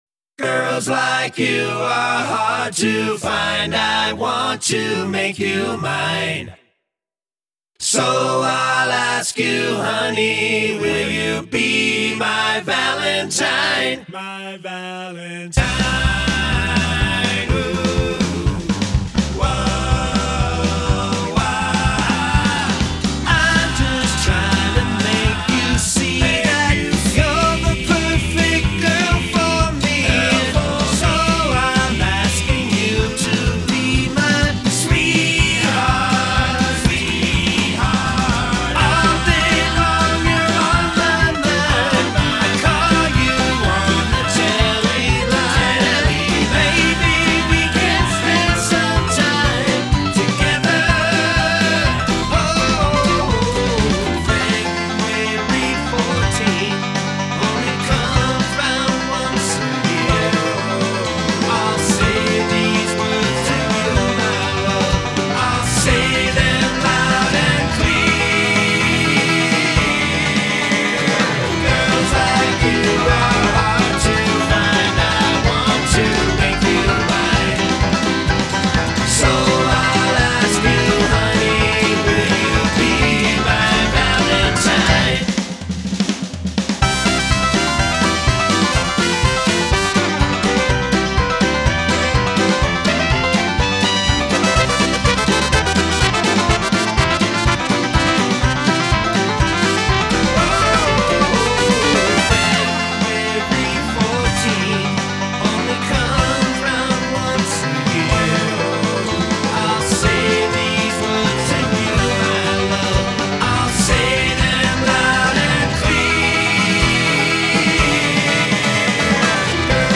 124 BPM
C Major
• 3 Large portions of Barbershop,
• A few handfuls of Doo-Wop,
• Equal portions of Ragtime and Honky-Tonk,
• And a sprinkle of Dixieland.
Vocals, Guitar, Bass
Piano, Instrumentation Arrangements